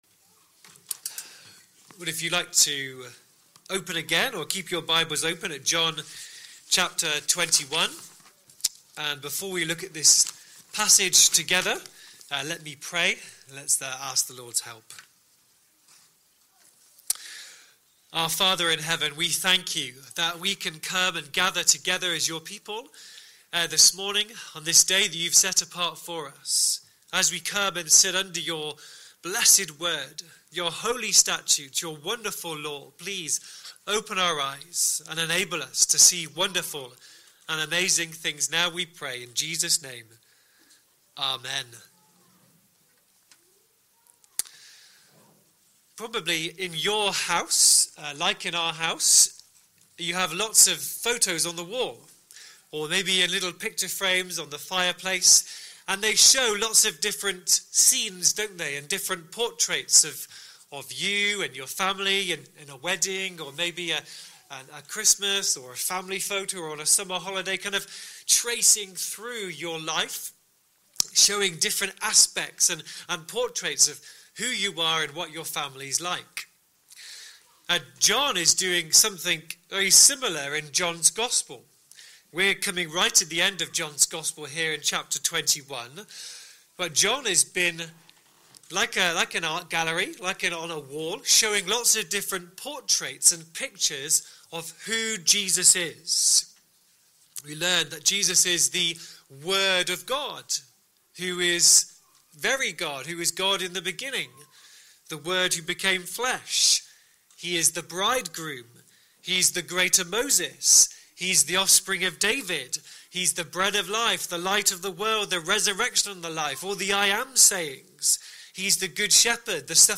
Sunday Morning Service Speaker